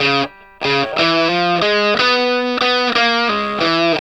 WALK1 60 CS.wav